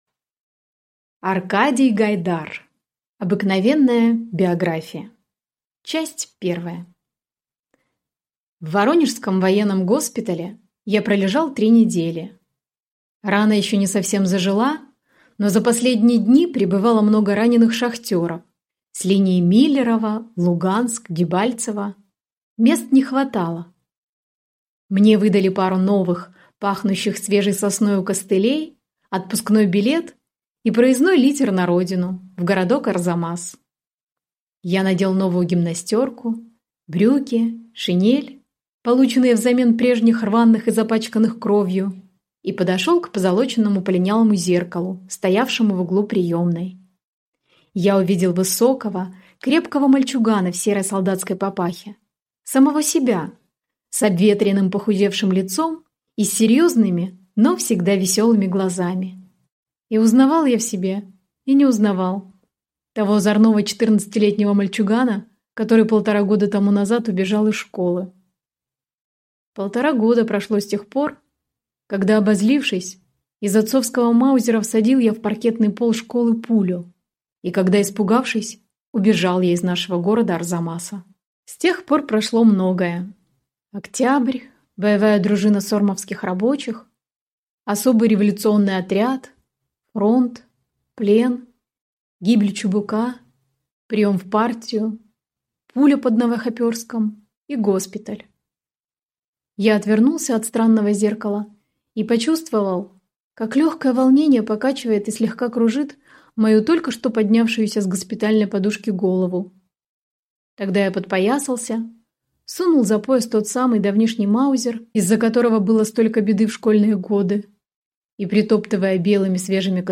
Аудиокнига Обыкновенная биография | Библиотека аудиокниг
Прослушать и бесплатно скачать фрагмент аудиокниги